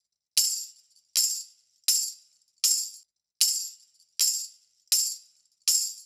Index of /musicradar/sampled-funk-soul-samples/79bpm/Beats
SSF_TambProc1_79-01.wav